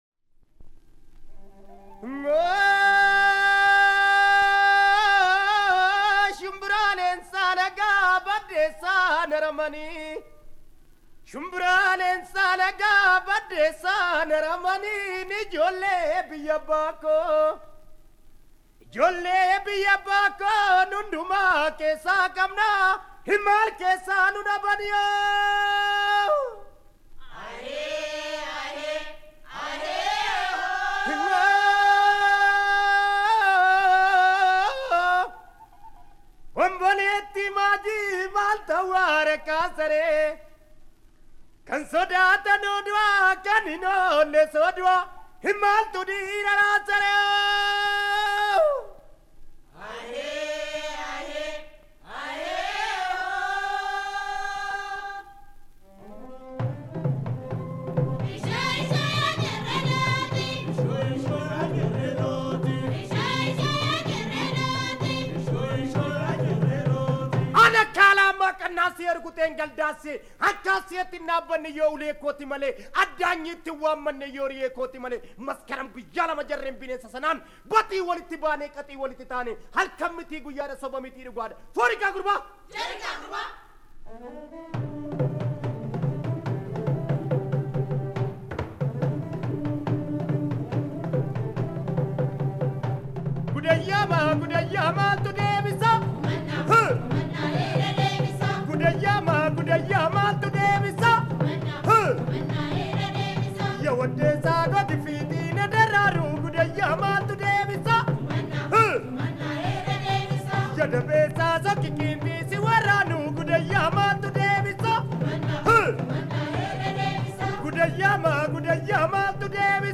Песня эфиопской революции.
Музыка: народная Слова